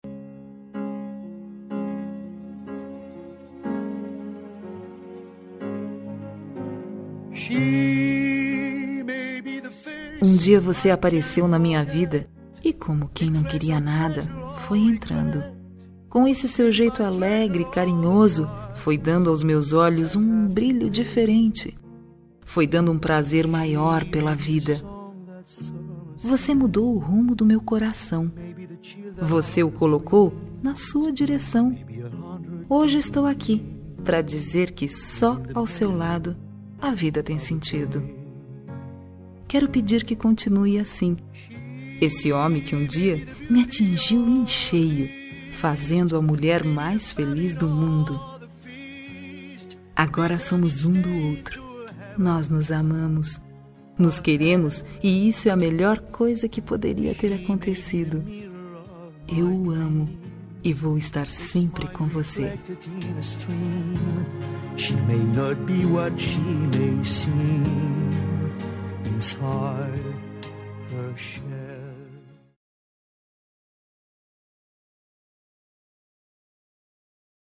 Romântica para Marido – Voz Feminina – Cód: 350332